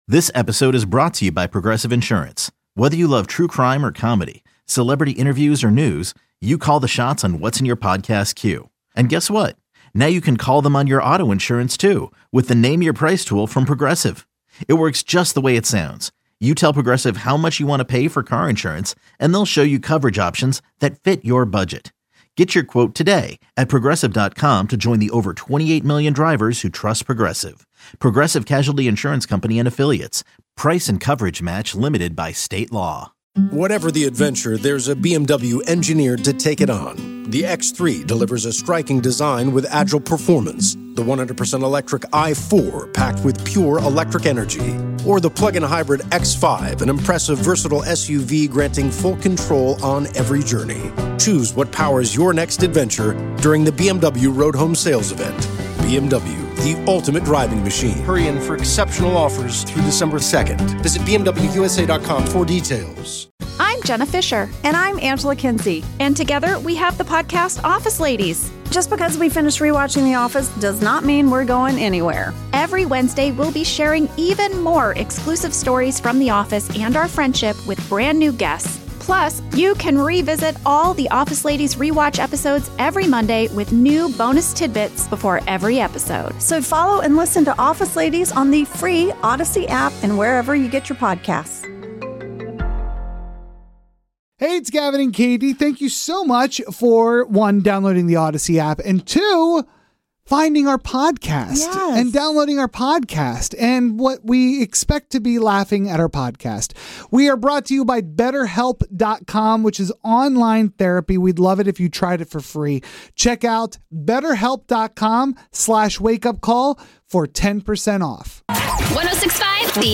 The Wake Up Call is a morning radio show based in Sacramento, California, and heard weekday mornings on 106.5 the End.